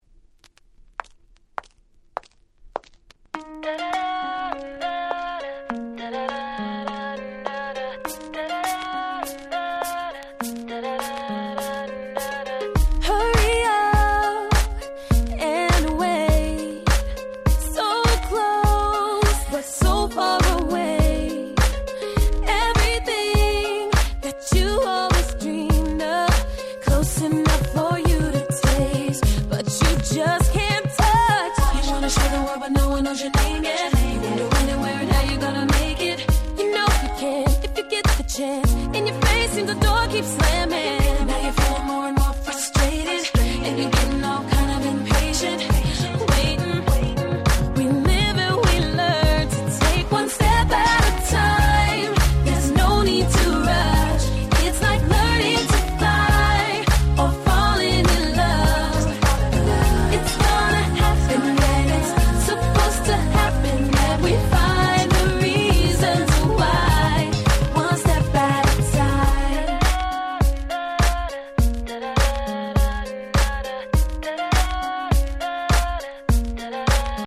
07’ Super Hit R&B !!